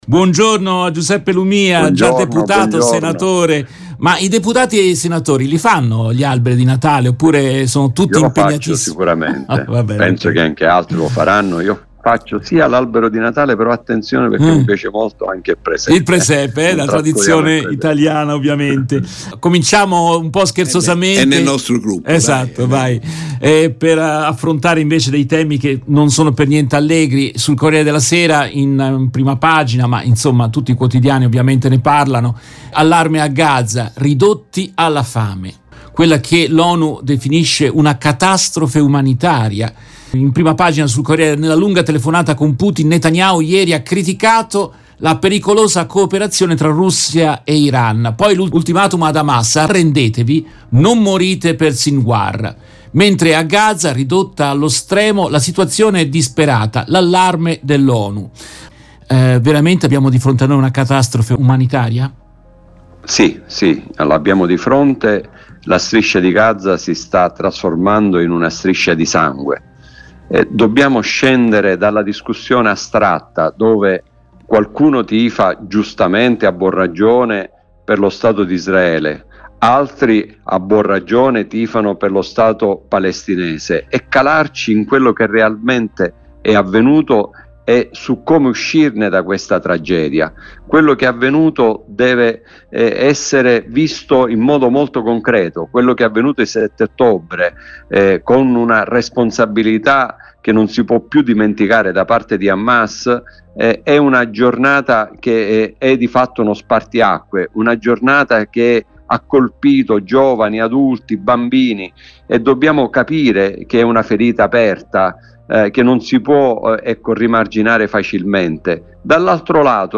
Nel corso della trasmissione in diretta del 11 dicembre 2023